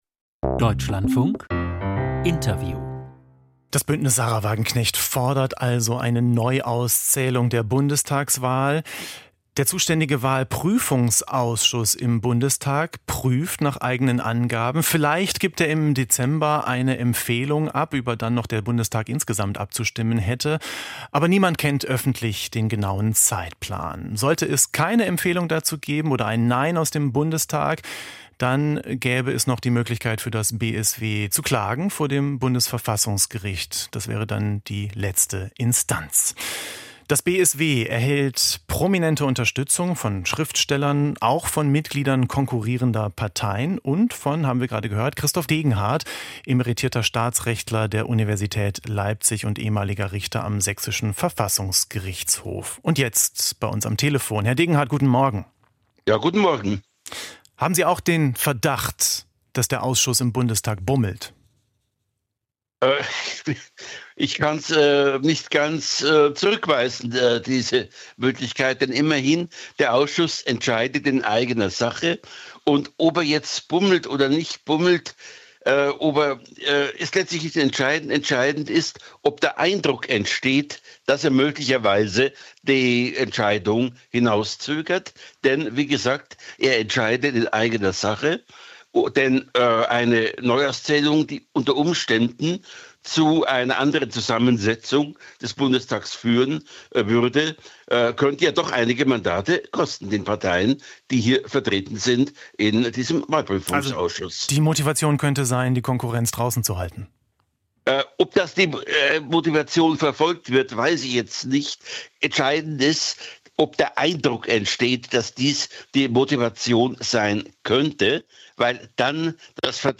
BSW-Neuauszählung - Interview